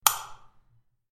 دانلود صدای کلید لامپ از ساعد نیوز با لینک مستقیم و کیفیت بالا
جلوه های صوتی